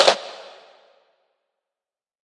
来自我的卧室的声音" Cable drop 3 ( Freeze )
描述：在Ableton中录制并略微修改的声音